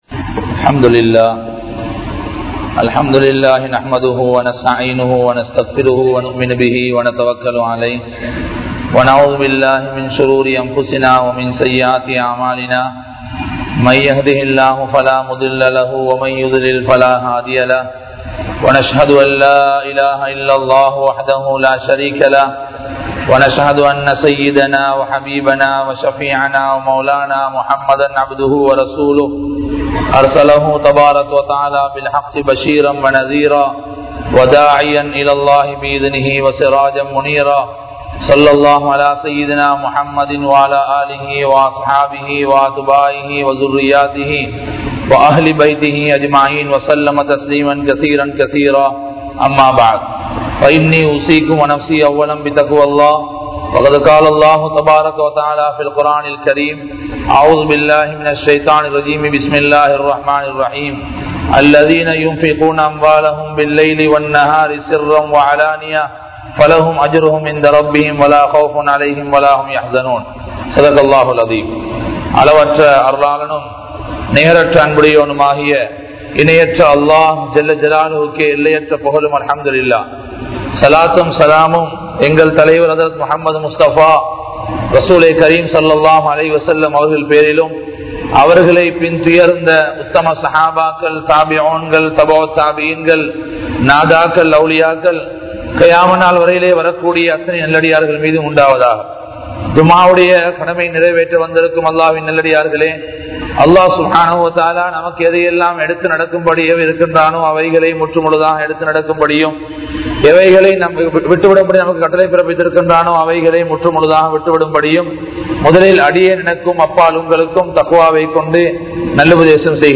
Masjidhoadu Thodarfudaiya Makkalin Sirappu (மஸ்ஜிதோடு தொடர்புடைய மக்களின் சிறப்பு) | Audio Bayans | All Ceylon Muslim Youth Community | Addalaichenai
Minnan Jumua Masjith